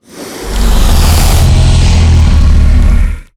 sucker_growl_1.ogg